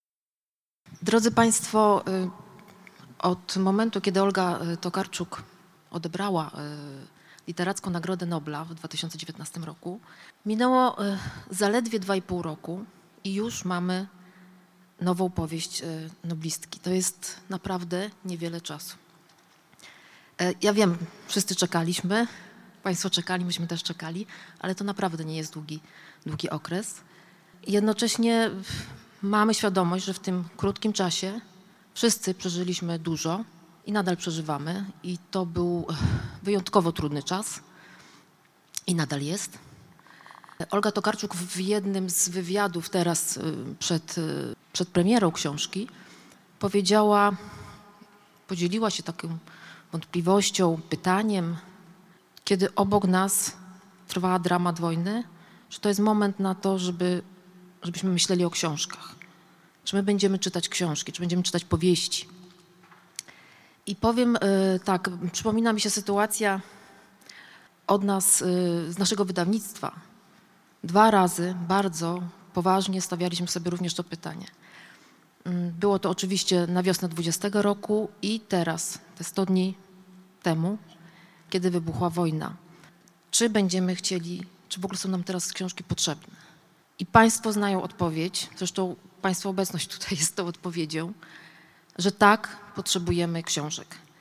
Wczoraj, 2 czerwca w Hali Stulecia miała miejsce oficjalna premiera nowej autorki Czułego narratora, Olgi Tokarczuk.